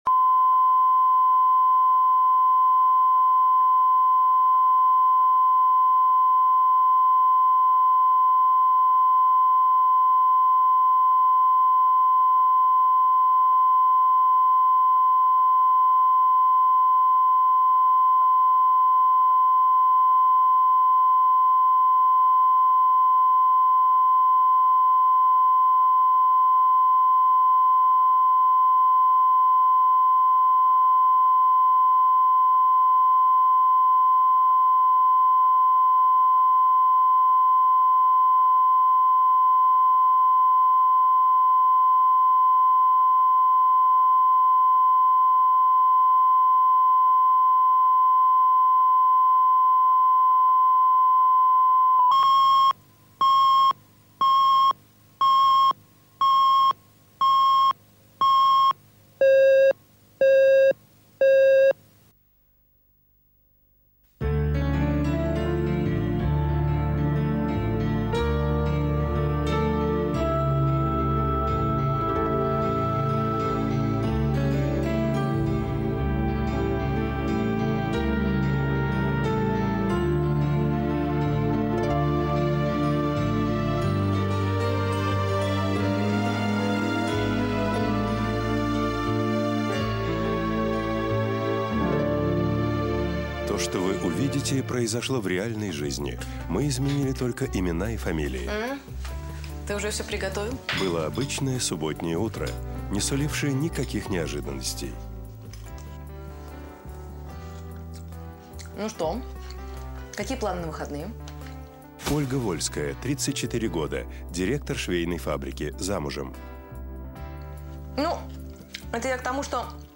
Аудиокнига Сильная женщина | Библиотека аудиокниг